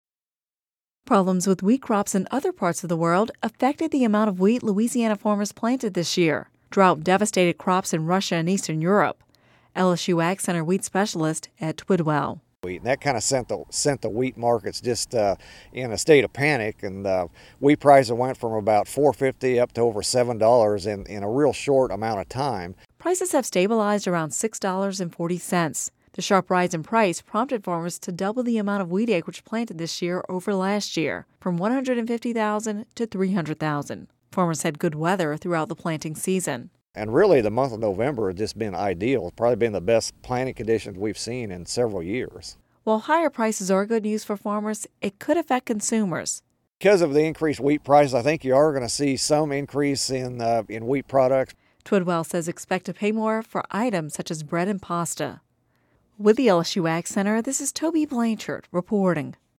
(Radio News 12/06/10) Problems with wheat crops in other parts of the world affected the amount of wheat Louisiana farmers planted this year. Drought devastated crops in Russia and Eastern Europe.